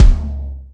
MB Kick (44).wav